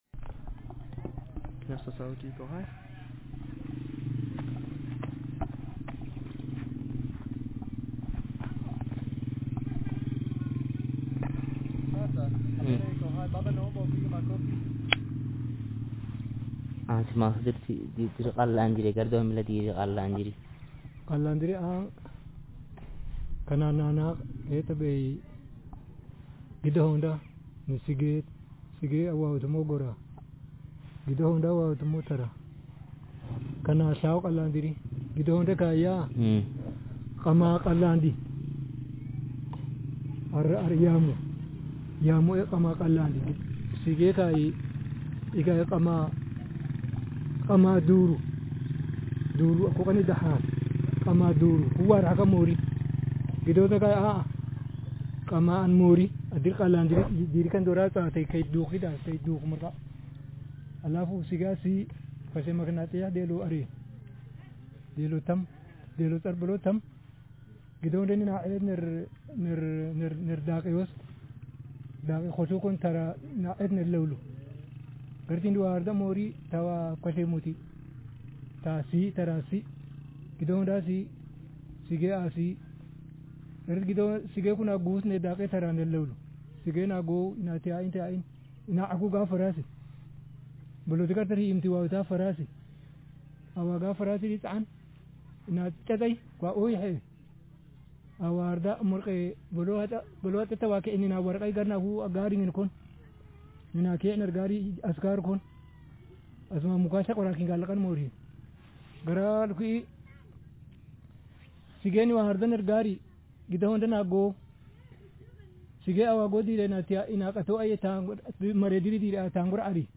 Speaker sexm/m
Text genreconversation